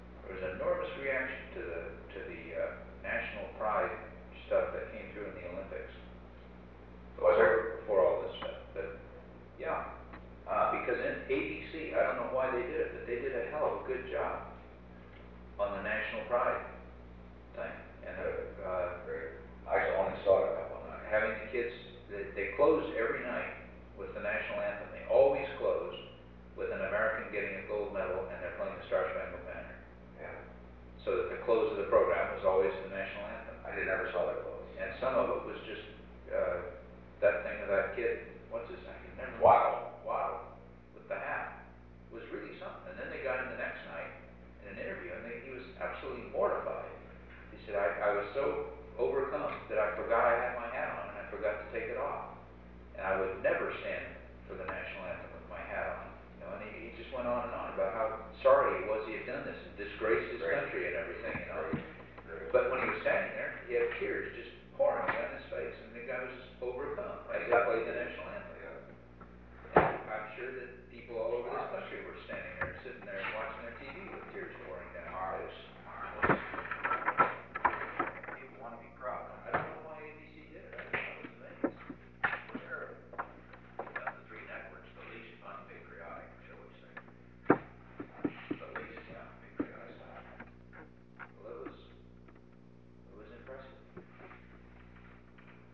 The Presidency / Featured Content 'The National Pride Thing' 'The National Pride Thing' Photo: Associated Press President Nixon and Chief of Staff H. R. “Bob” Haldeman reflected on the media’s treatment of the Munich Olympics and the willingness of the ABC television network to close its nightly broadcasts with the playing of the national anthem, a decision they greeted with both appreciation and astonishment. Date: September 6, 1972 Location: Oval Office Tape Number: 770-001 Participants Richard M. Nixon H. R. “Bob” Haldeman Associated Resources Audio File Transcript